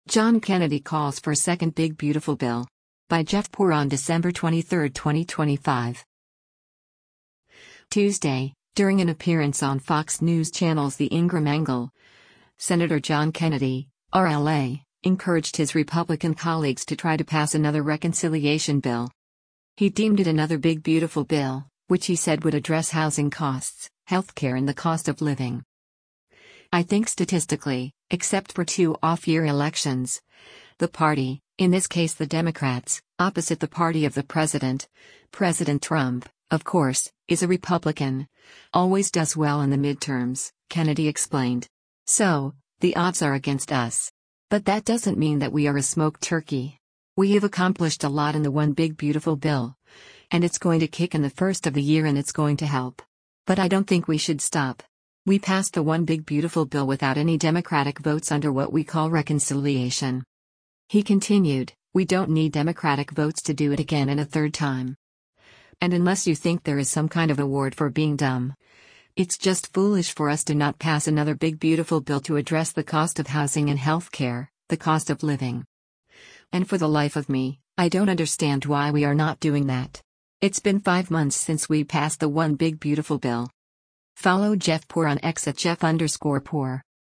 Tuesday, during an appearance on Fox News Channel’s “The Ingraham Angle,” Sen. John Kennedy (R-LA) encouraged his Republican colleagues to try to pass another reconciliation bill.